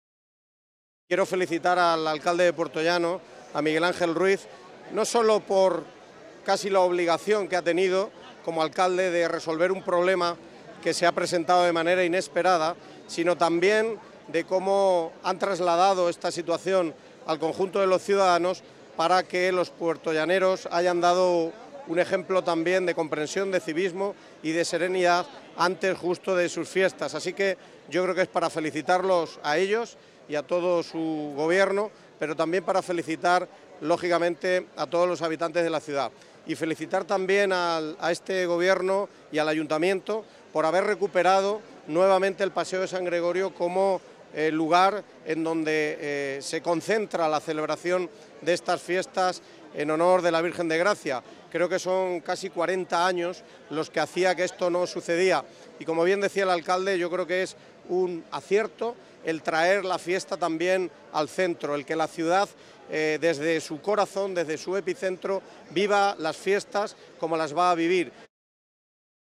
Durante la inauguración de la feria que tuvo lugar anoche, tanto el alcalde de Puertollano, Miguel Ángel Ruiz, como el presidente de la Diputación de Ciudad Real, Miguel Ángel Valverde, han compartido un mensaje de optimismo y gratitud.